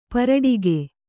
♪ paraḍige